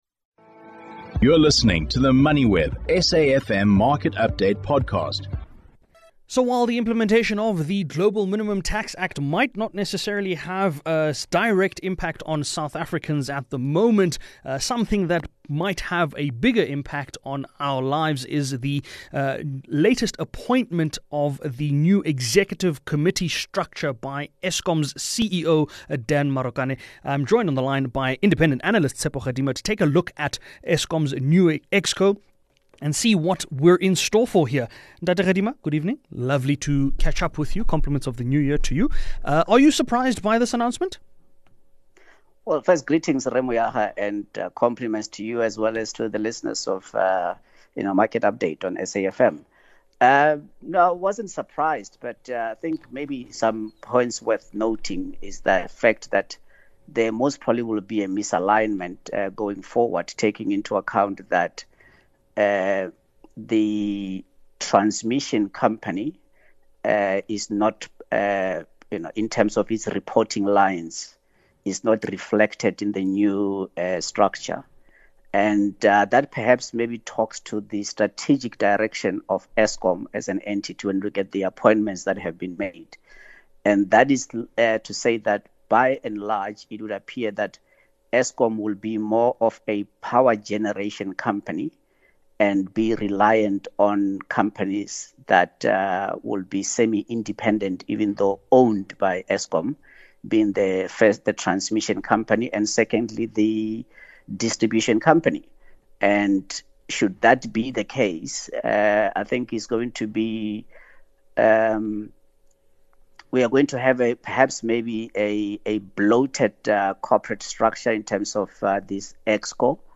The programme is broadcasted Monday to Thursday nationwide on SAfm (104 – 107fm), between 18:00 and 19:00.